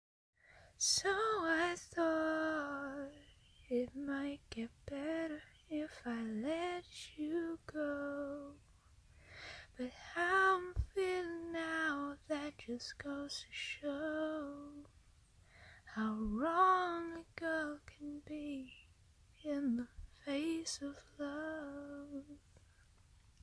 描述：一个女性的声音在唱着这样的话：“我要走远了，远离家乡”
标签： 唱歌 声音 女孩 唱歌 英语 女性 音乐 采样 人声 歌曲 歌手
声道立体声